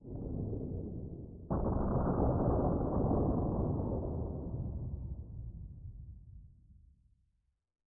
Minecraft Version Minecraft Version latest Latest Release | Latest Snapshot latest / assets / minecraft / sounds / ambient / nether / nether_wastes / addition1.ogg Compare With Compare With Latest Release | Latest Snapshot